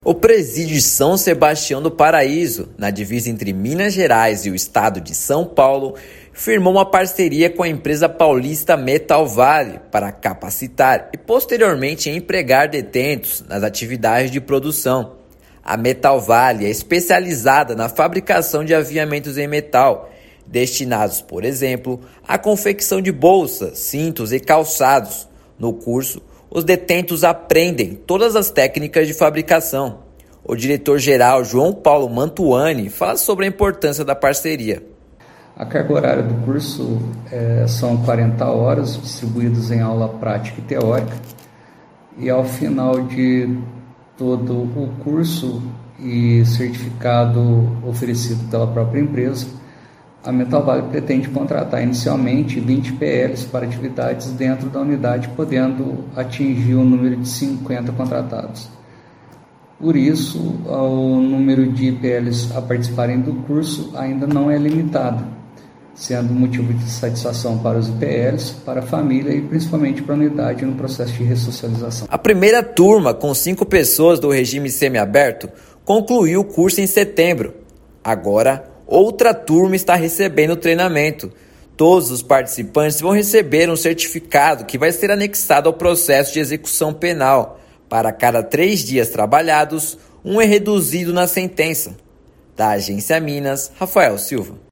[RÁDIO] Detentos de São Sebastião do Paraíso participam de curso profissionalizante
Empresa parceira do sistema prisional já capacitou 25 custodiados que vão atuar na fábrica que será instalada no presídio. Ouça matéria de rádio.